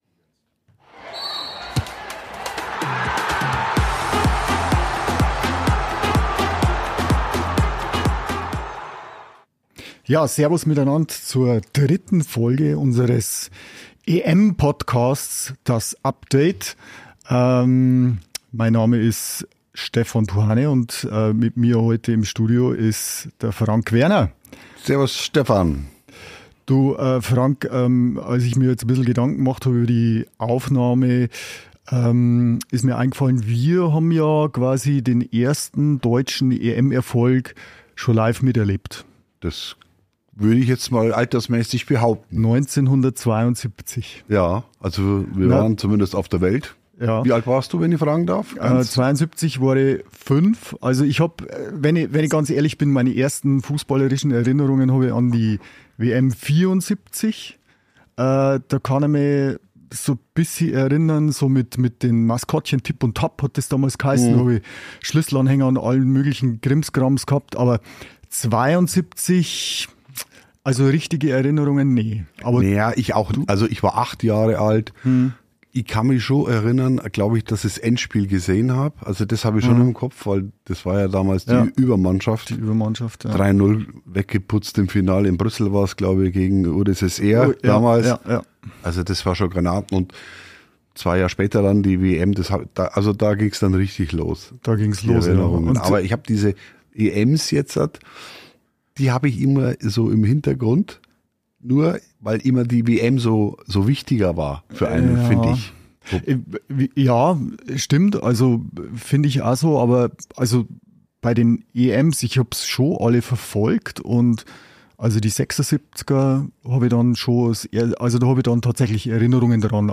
Aber natürlich geht es auch um die Sticker für die EM 2024. Außerdem wird es musikalisch.